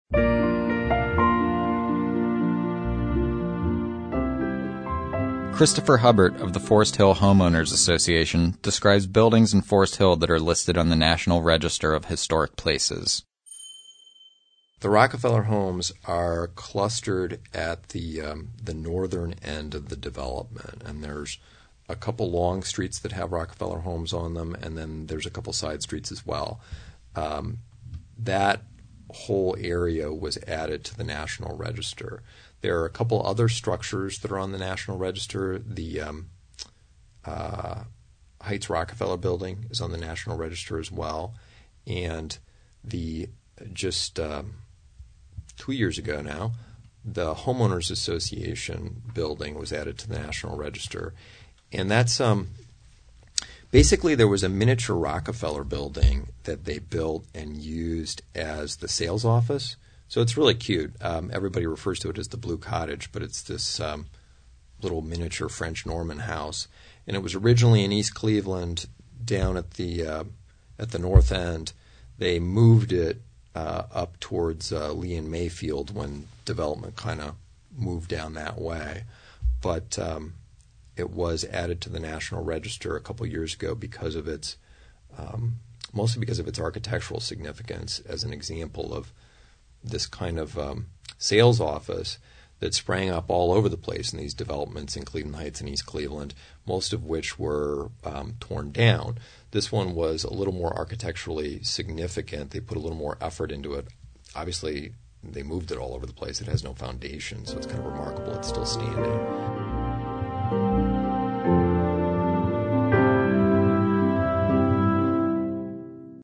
Source: Cleveland Regional Oral History Collection